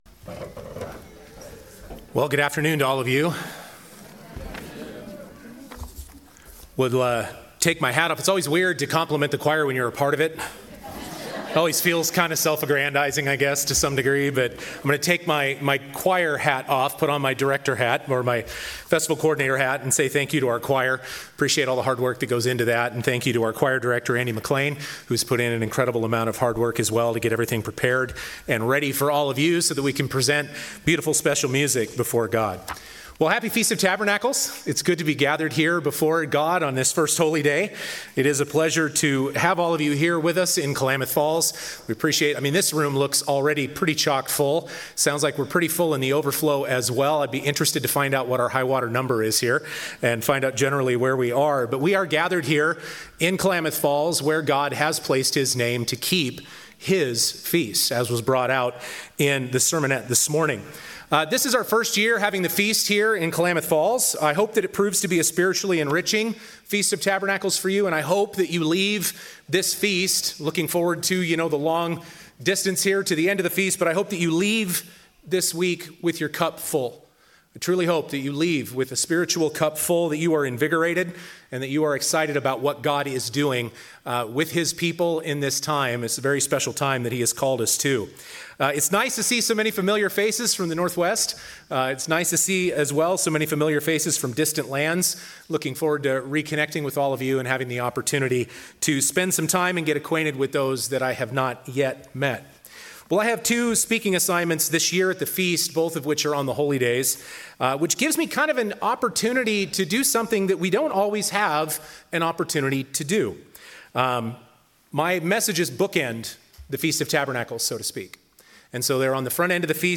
Sermon - Day 1 - Feast of Tabernacles in Klamath Falls, Oregon
This sermon was given at the Klamath Falls, Oregon 2024 Feast site.